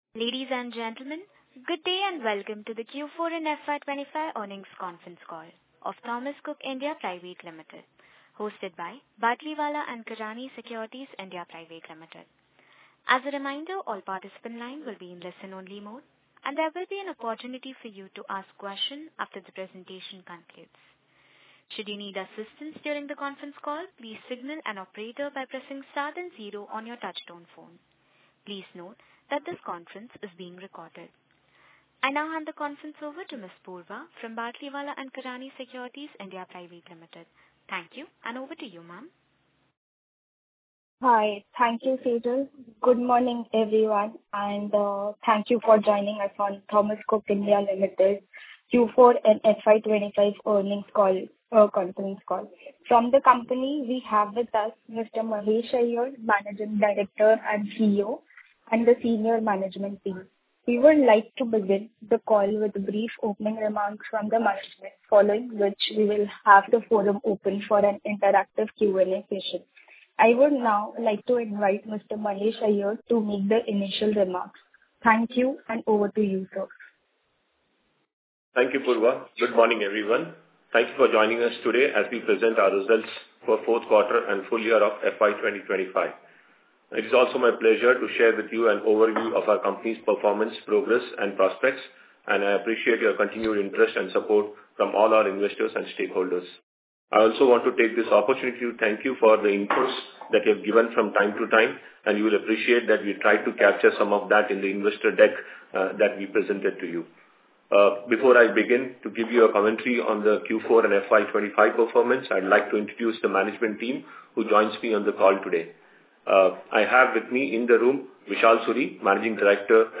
Concalls
TCIL Q4FY25 Earnings Call Audio recording.mp3